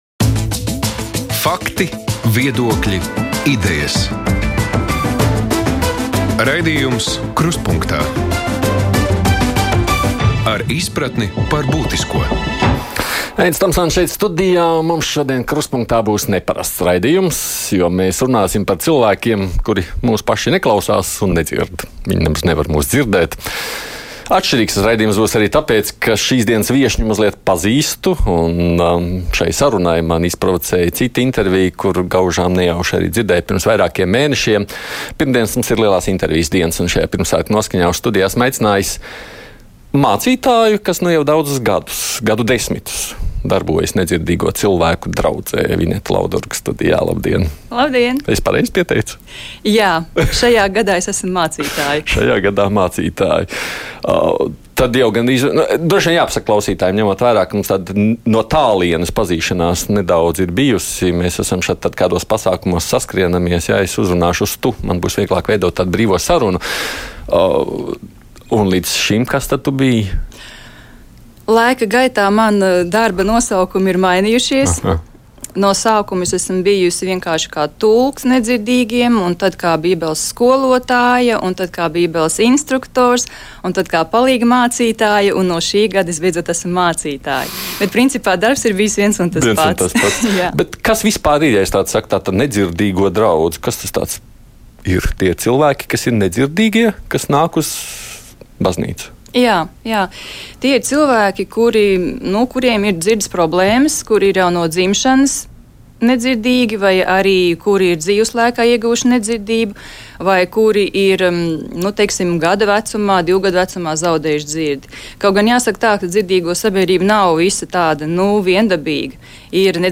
Studijā